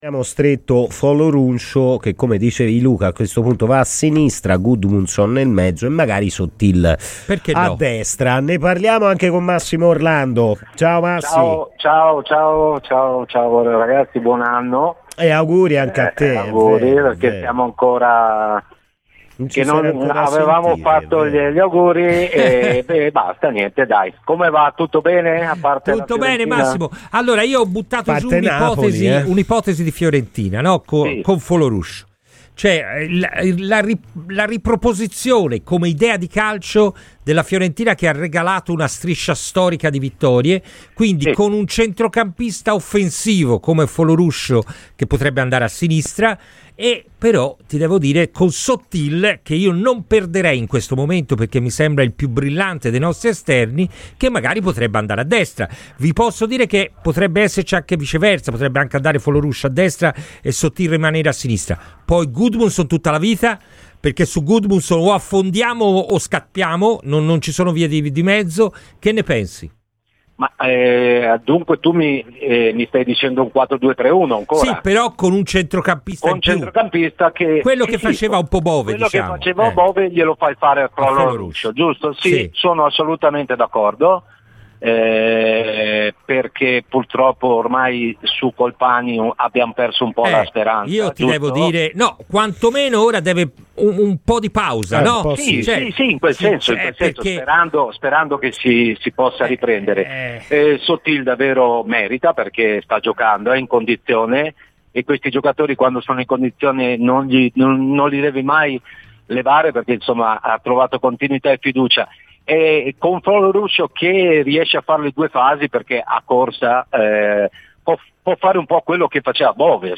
L'ex calciatore della Fiorentina Massimo Orlando è intervenuto ai microfoni di Radio FirenzeViola durante la trasmissione "Palla al Centro" per parlare delle tematiche più attuali di casa Fiorentina.